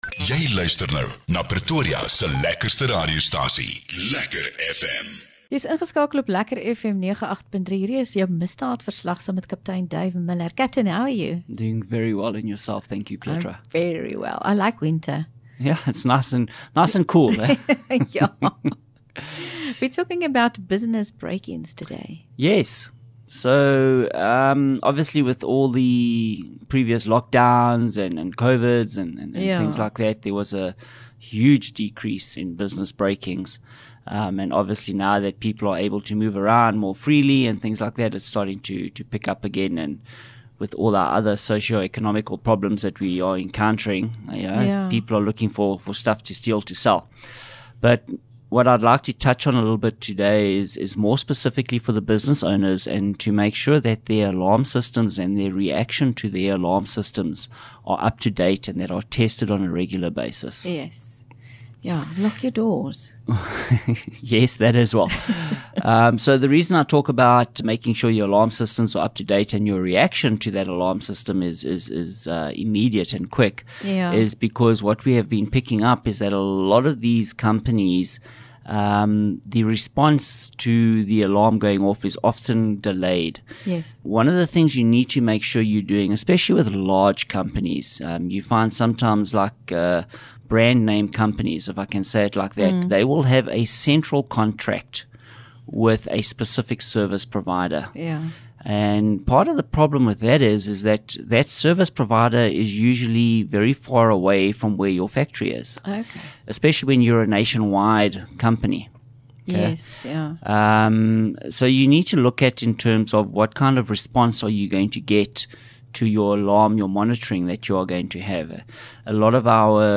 LEKKER FM | Onderhoude 6 Jun Misdaadverslag